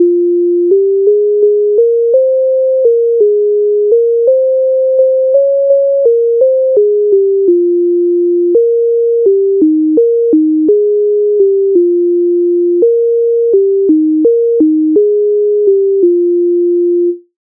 MIDI файл завантажено в тональності f-moll
Ой послала мене мати Українська народна пісня з обробок Леонтовича с,126